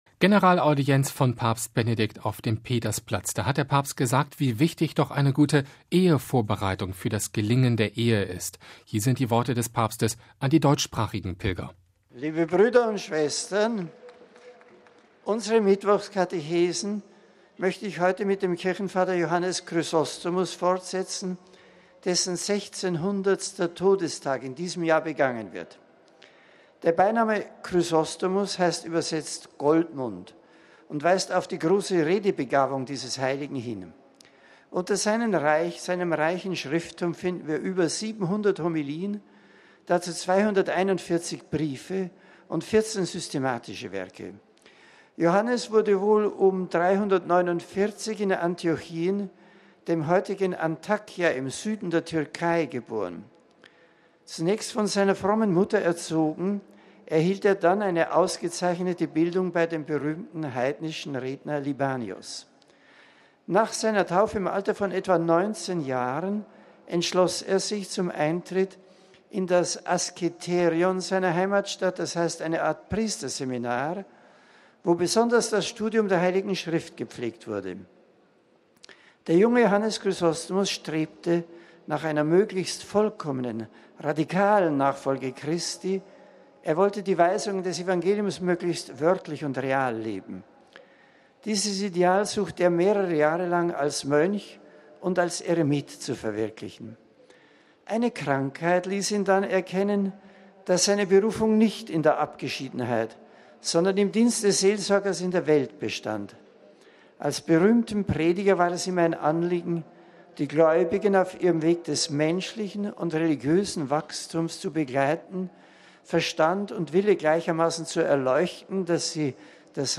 Bei der Generalaudienz auf dem Petersplatz bekräftigte er die Beziehung von Glaube und Vernunft.
Hier sind die Worte des Papstes an die deutschsprachigen Pilger: